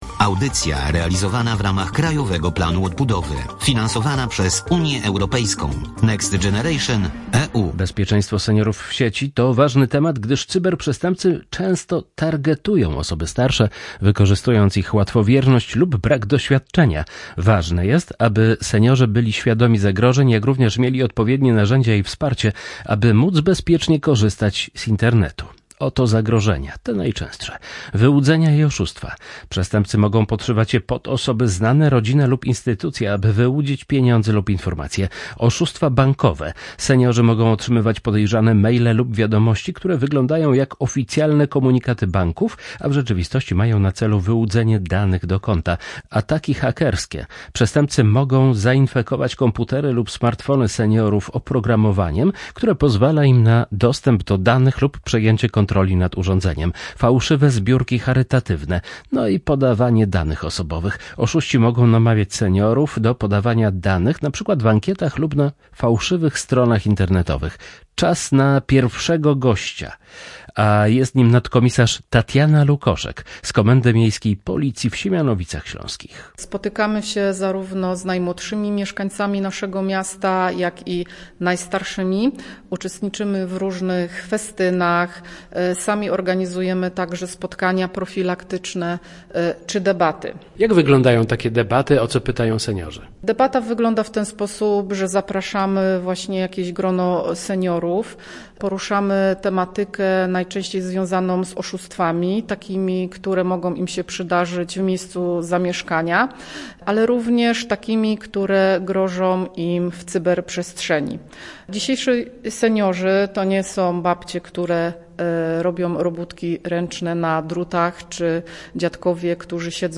Nagranie audio audycja "W cyfrowym świecie"